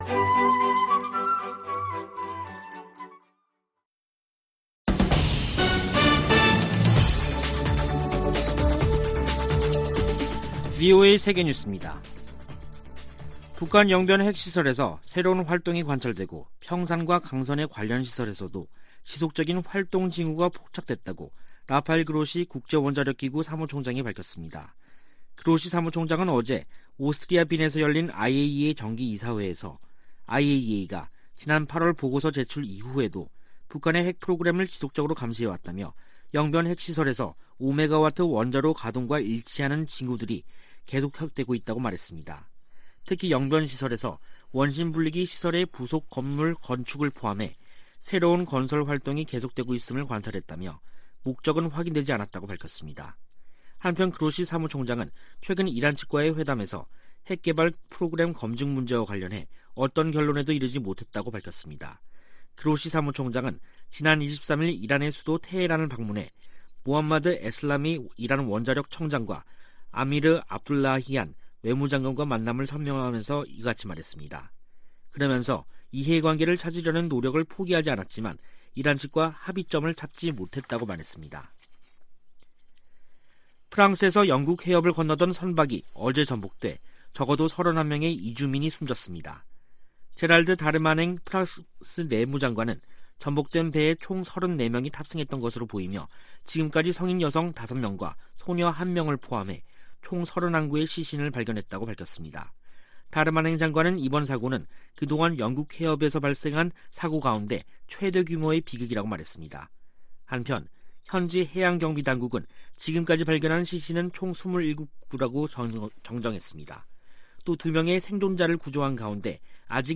VOA 한국어 간판 뉴스 프로그램 '뉴스 투데이', 2021년 11월 25일 3부 방송입니다. 국제원자력기구(IAEA)는 영변 핵 시설에서 새로운 활동이 관찰되고 평산과 강선의 관련 시설에서도 지속적인 활동 징후가 포착됐다고 밝혔습니다. 북한은 의도적 핵활동 노출로 미국을 압박하고 이를 협상력을 높이는 지렛대로 활용하려는 계산이라고 한국의 전문가가 분석했습니다. 미국과 한국의 한국전쟁 종전선언 논의가 문안을 마무리하는 단계에 있다고, 미국 정치 전문 매체가 보도했습니다.